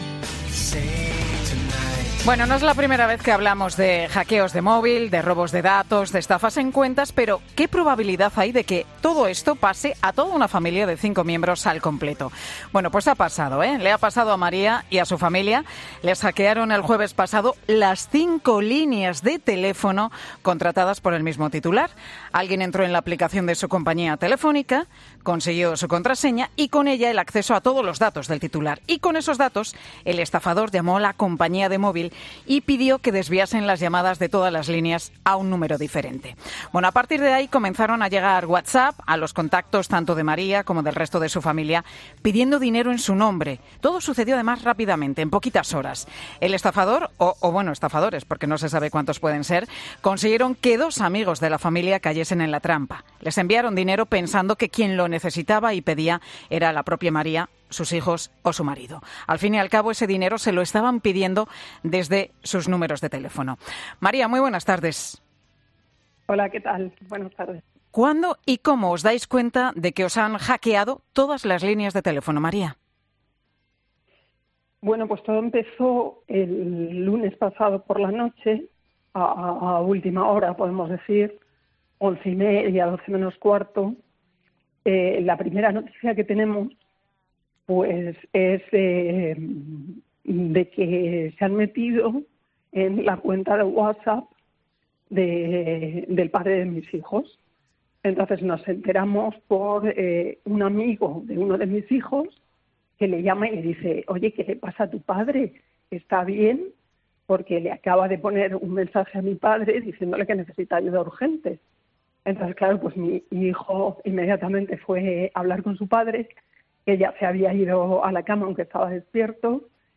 Le tiembla de rabia e indignación porque las consecuencias no solo las han sufrido ellos, sino también sus amigos, que son los que han puesto dinero para ayudarles "supuestamente" a ellos.